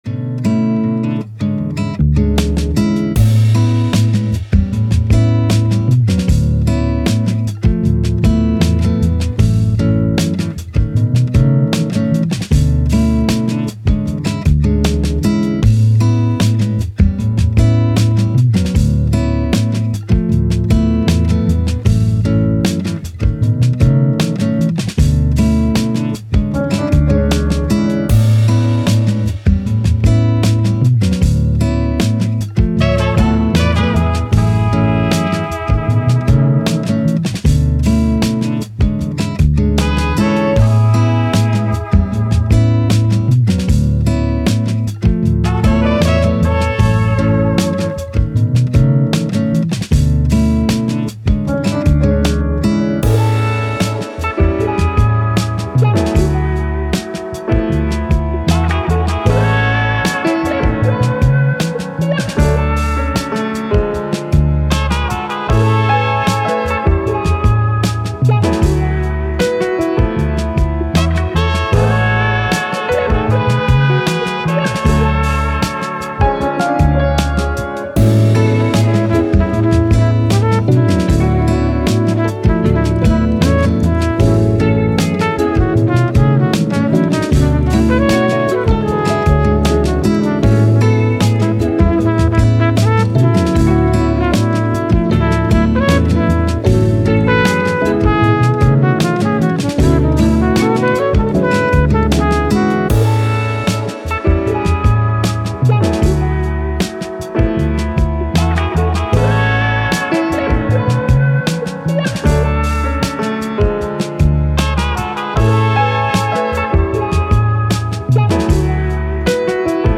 Soul, Happy, Chill, Positive, Trumpet